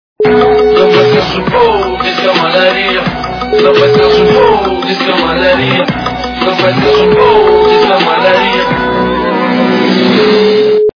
- русская эстрада
При заказе вы получаете реалтон без искажений.